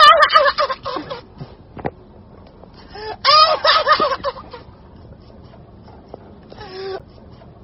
장르 효과음